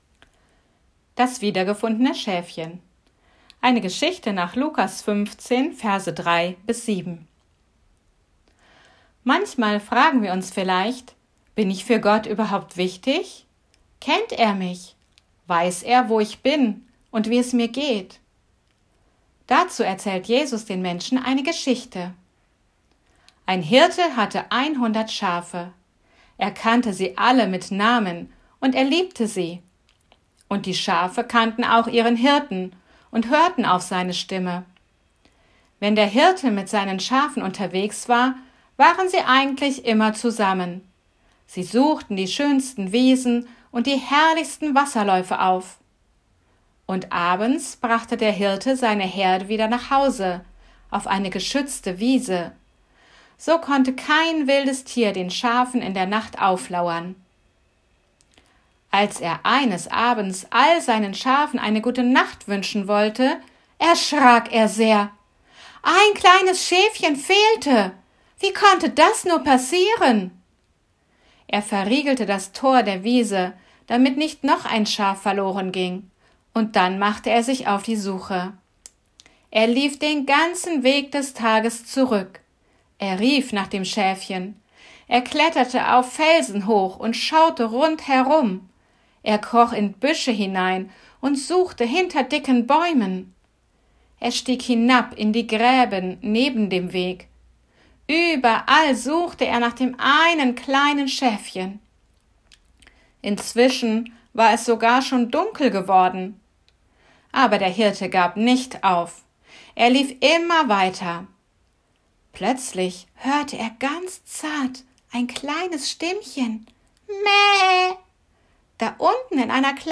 Geschichte mit Audio und Bastelidee